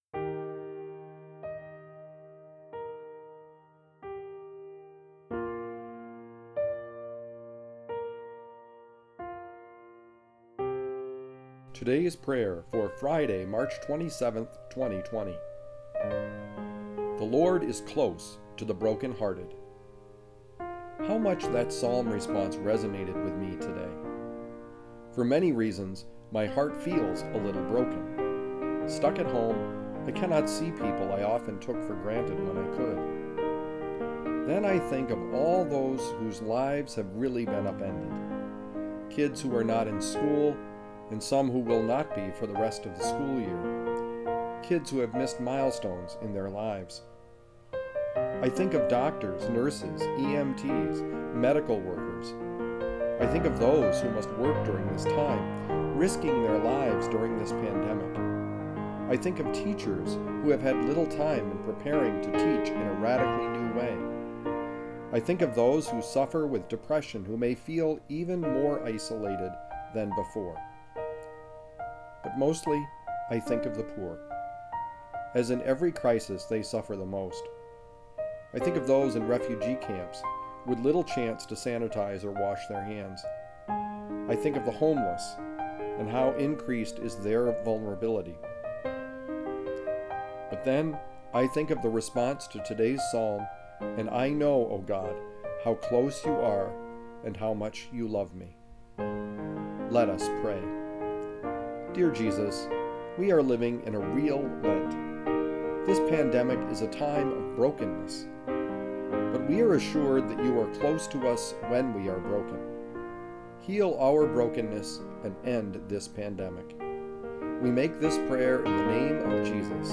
Today’s Prayer: March 27, 2020
Music: Cheezy Piano Medley by Alexander Nakarada